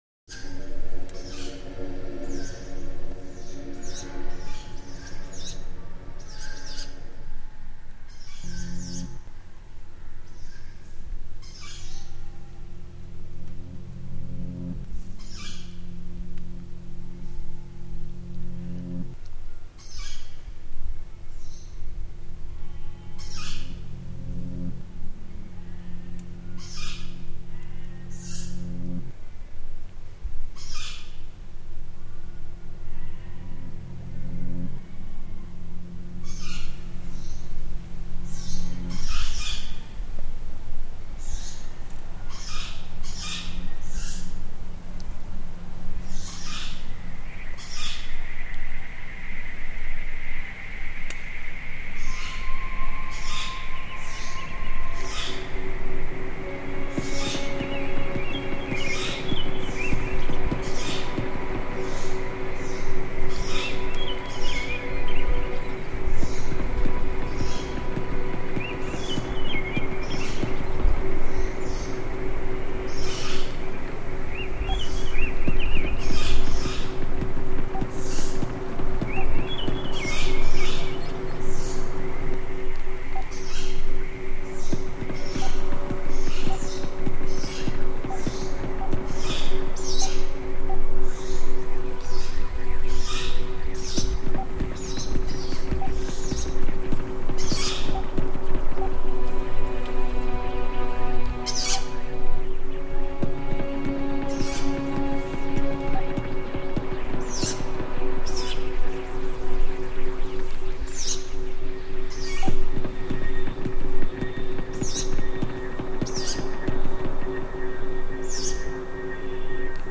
Owls in the Brecon Beacons reimagined